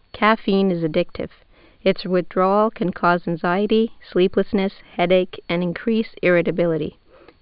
s2女性英語　　Coffee is addictive ...